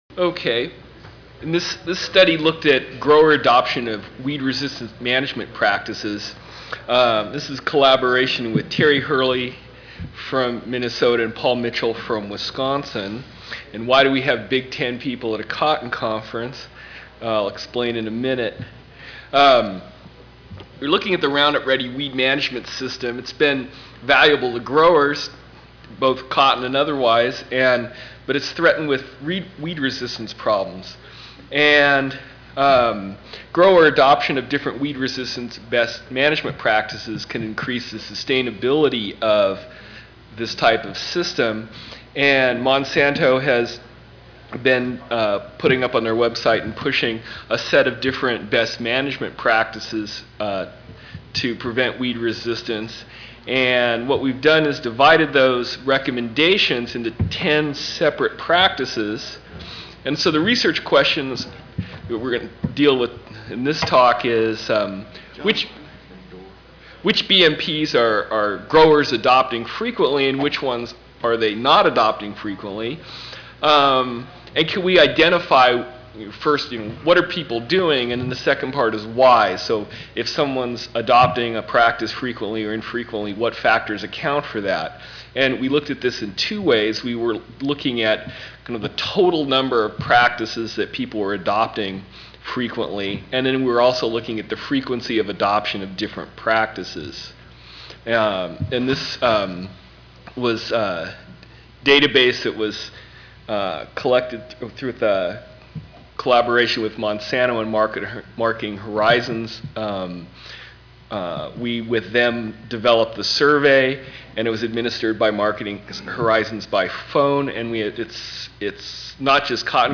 University of Wisconsin Audio File Recorded presentation